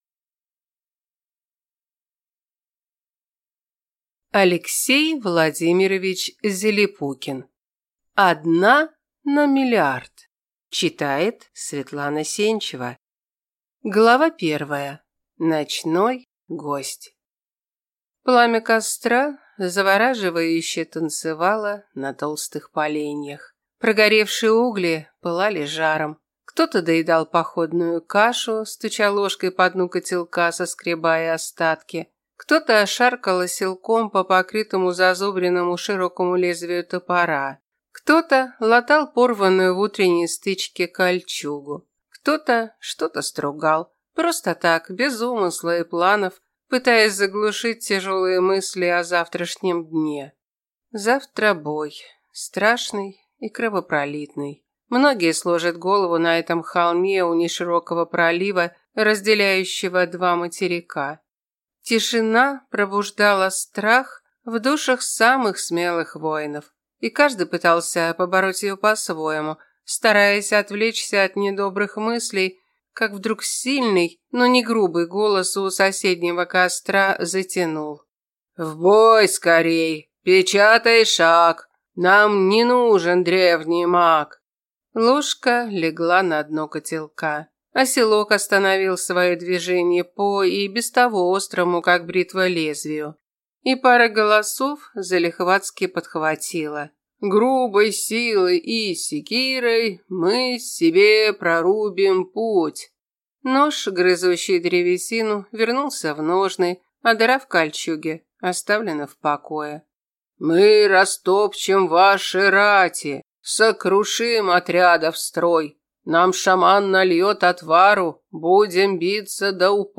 Аудиокнига Одна на миллиард | Библиотека аудиокниг